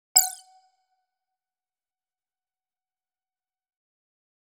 notification-error.2Y8jlTwp.mp3